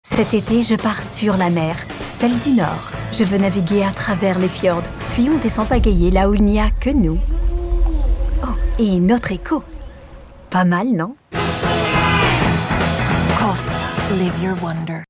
PUBLICITÉ TV– COSTA CROISIÈRE (français)
VOIX PETILLANTE